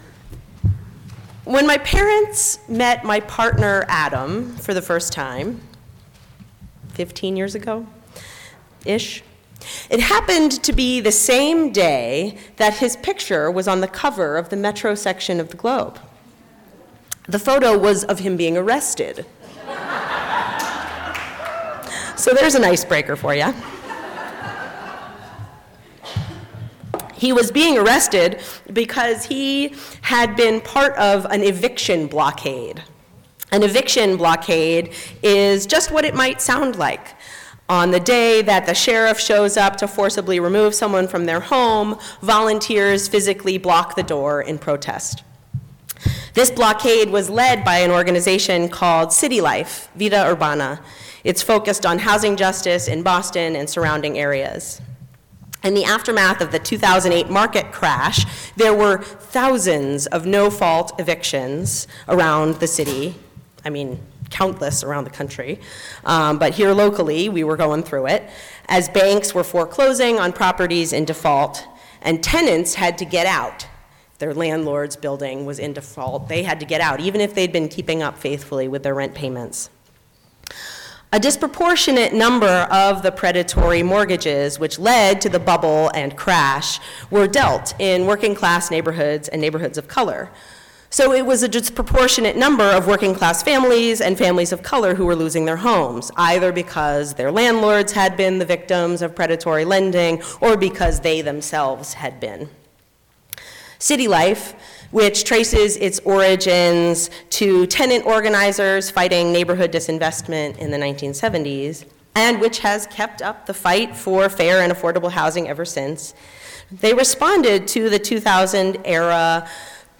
In today’s service we will reflect on organizing. What is organizing and what does it have to do with the call of our UU faith?